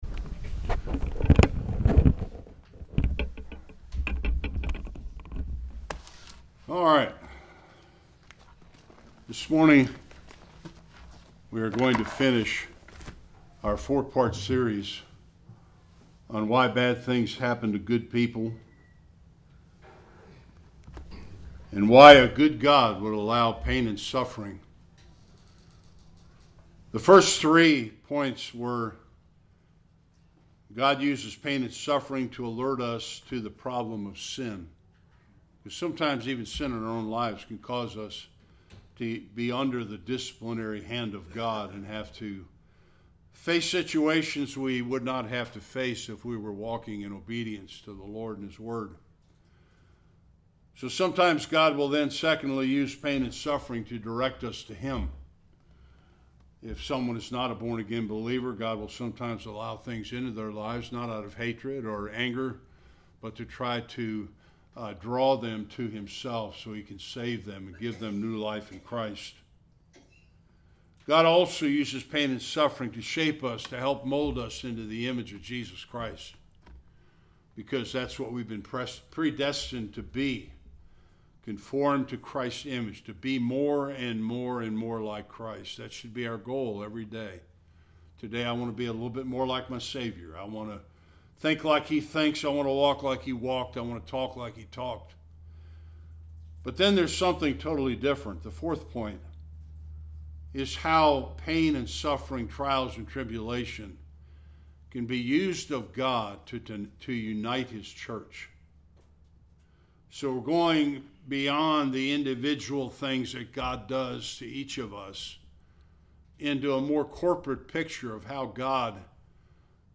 Various Passages Service Type: Sunday Worship How does God use pain and suffering to unite us in the church and build it up?